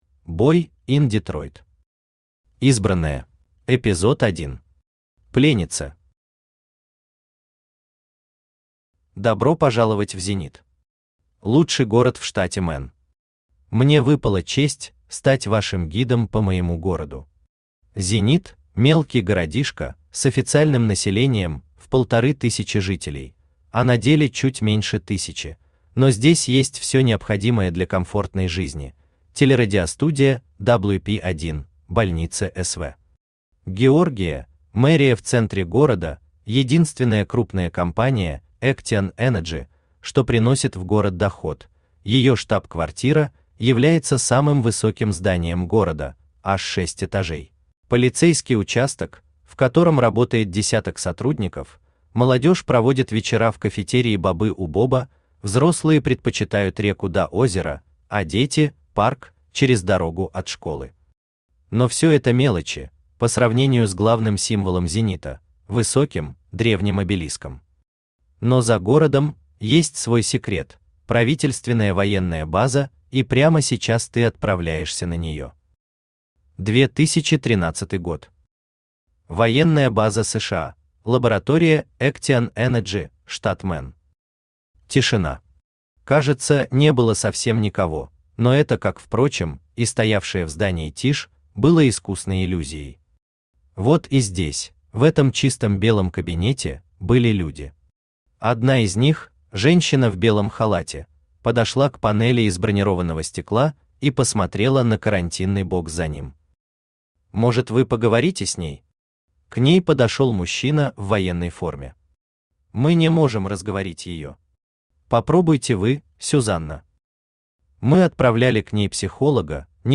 Аудиокнига Избранная | Библиотека аудиокниг
Aудиокнига Избранная Автор Boy in Detroit Читает аудиокнигу Авточтец ЛитРес.